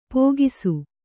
♪ pōgisu